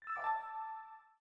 Звук колокольчиков Chimes